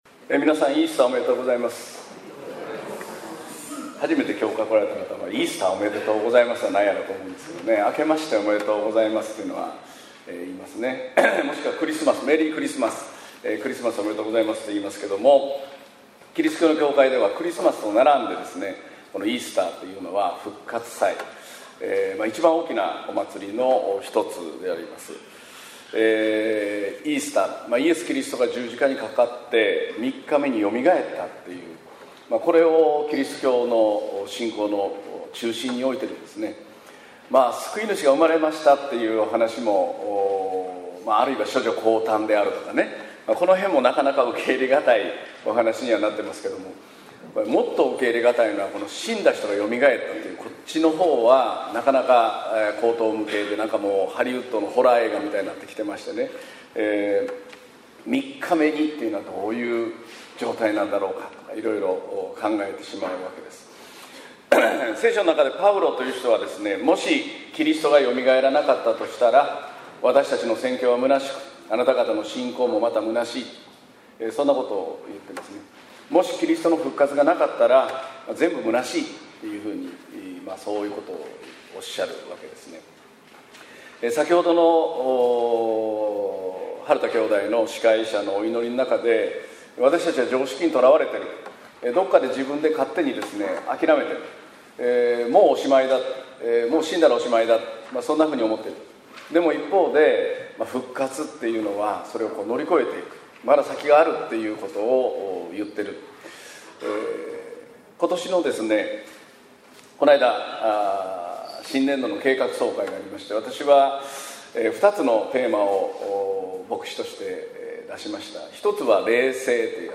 2018年4月1日（日）イースター礼拝 宣教題「神の決断―信仰先に立たず」 | 東八幡キリスト教会
宣教